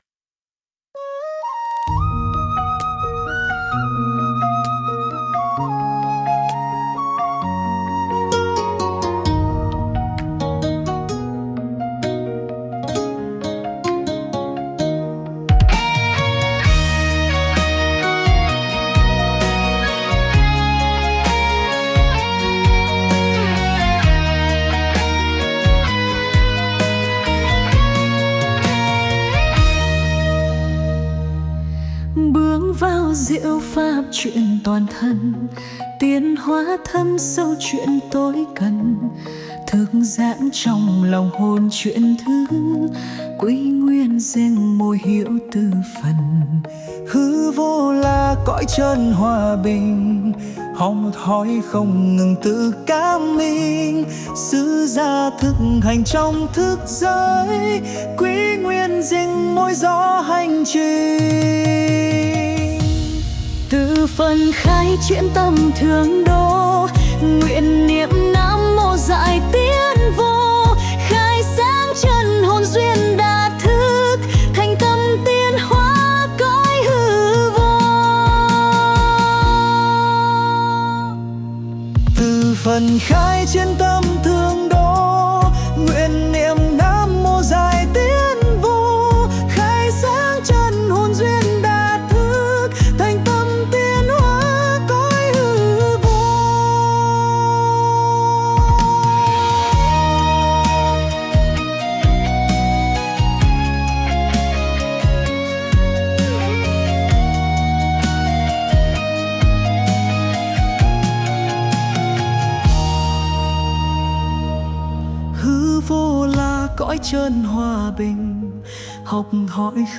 NHẠC THƠ